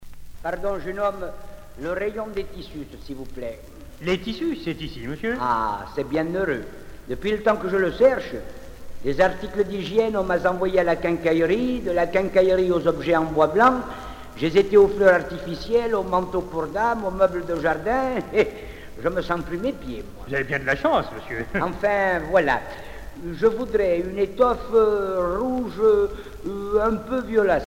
Genre sketch